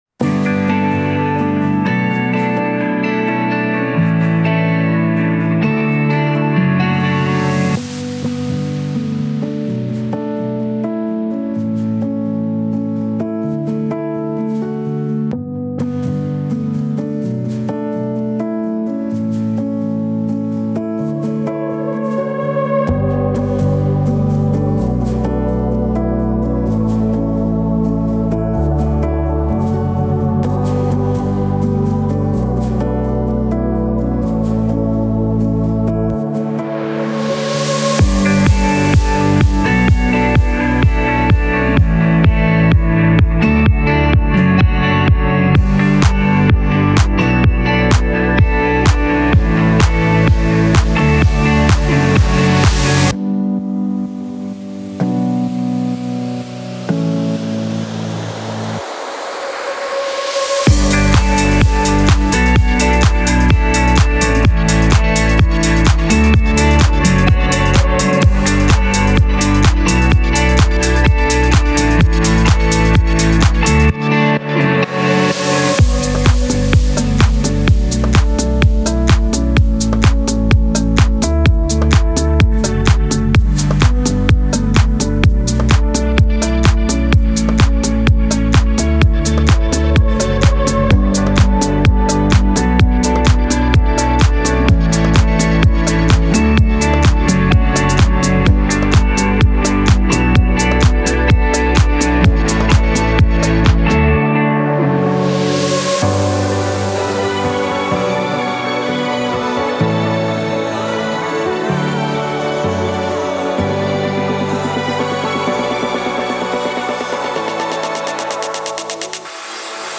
Here's the official instrumental of